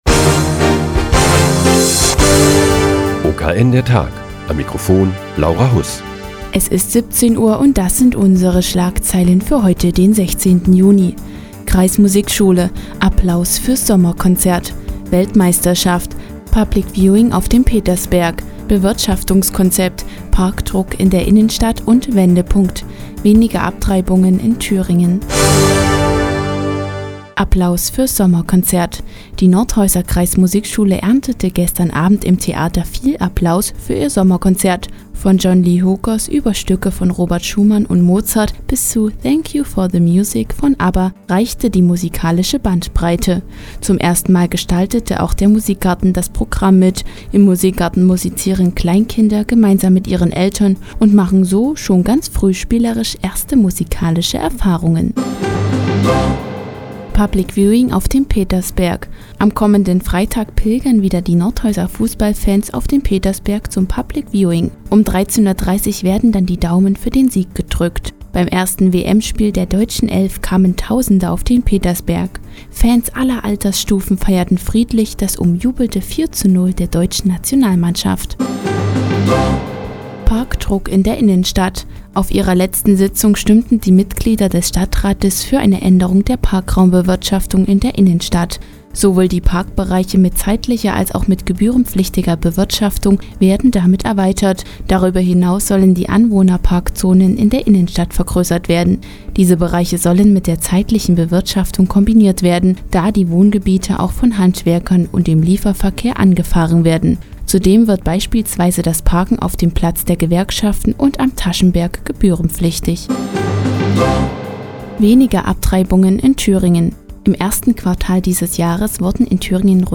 Die tägliche Nachrichtensendung des OKN ist nun auch in der nnz zu hören. Heute geht es um das Sommerkonzert der Nordhäuser Kreismusikschule und Public Viewing auf dem Petersberg.